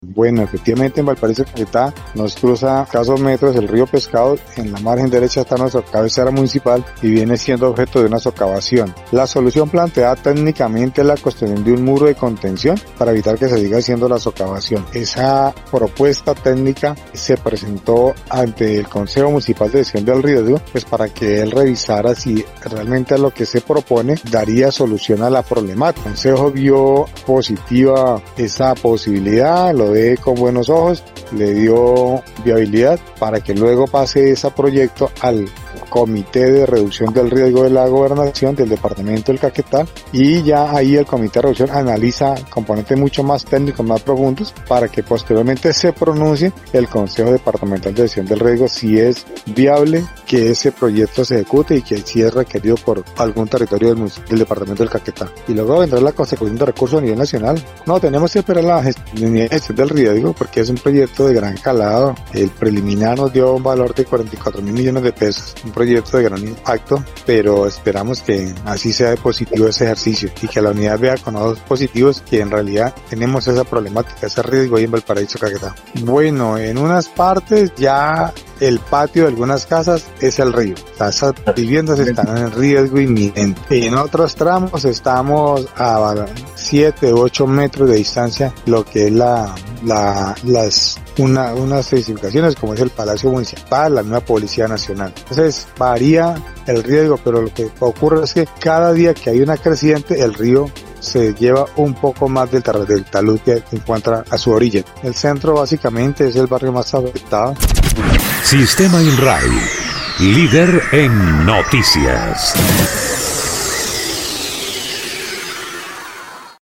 El alcalde del municipio de Valparaíso, Arbenz Pérez Quintero, dijo que, ante este problema, el consejo municipal de gestión de riesgo aprobó un proyecto para gestionar recursos a nivel departamental y nacional para construir un muro de contención de 600 metros por un valor de $44.000 millones.
03_ALCALDE_ARBENS_PEREZ_QUINTRO_OBRA.mp3